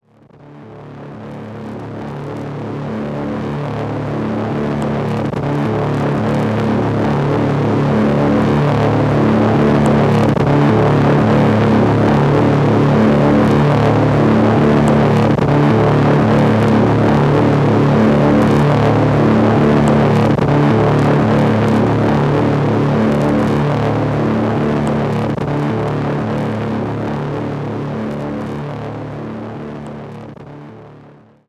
Last night I found a pretty interesting sound as I was playing with some new effects pedals. I decided it was time to dive in and try recording it onto a tape loop.
bitcrusher-tape-loop.mp3